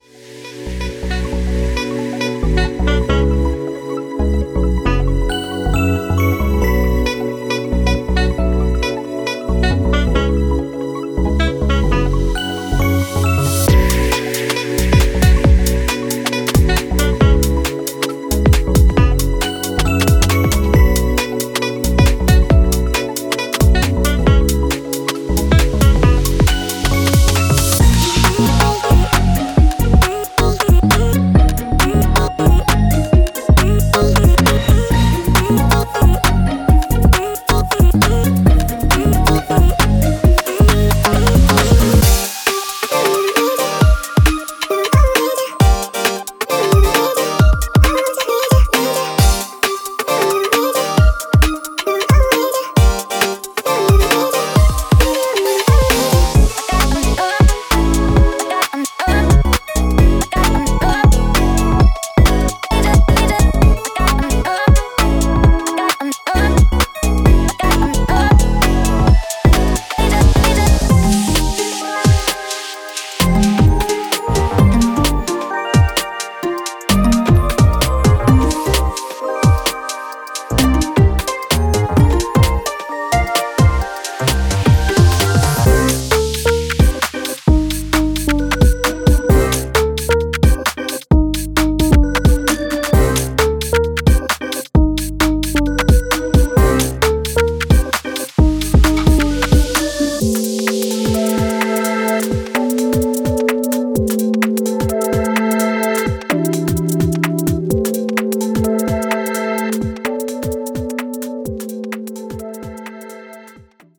Genre:Garage
シャープなハイハット、刻みのスネア、130 BPM前後で力強くスウィングするシンコペーションパーカッションが特徴です。
キーボードスタブ、ジャジーなリフ、フィルター付きコード進行など、品格と個性を感じさせる素材が豊富です。
デモサウンドはコチラ↓